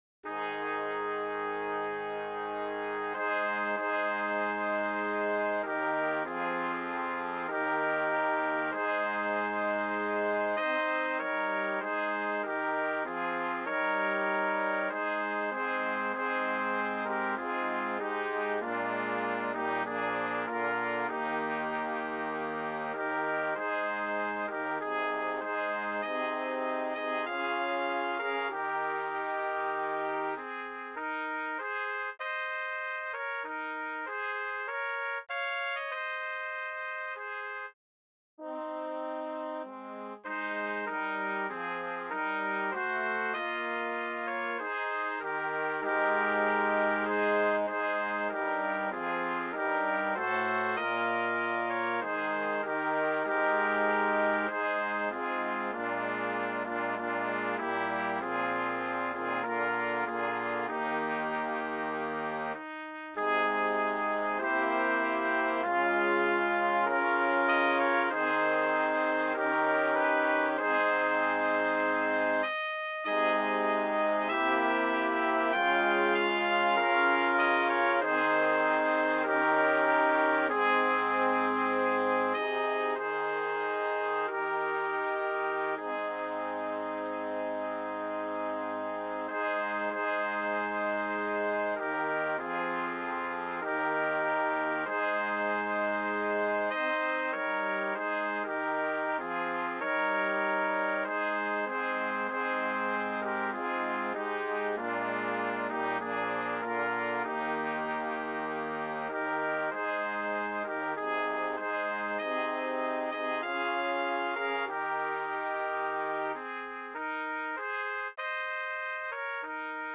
BRASS QUINTET
FOR STANDARD BRASS QUINTET